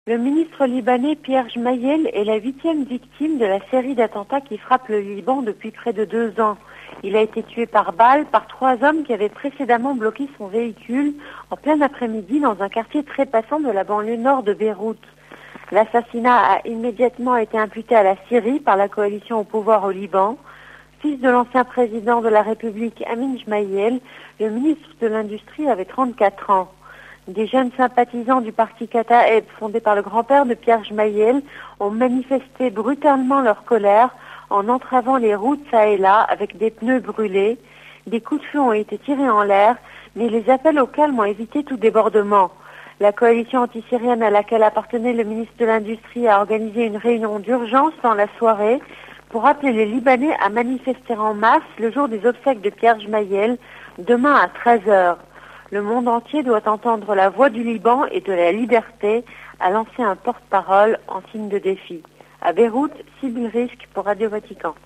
A Beyrouth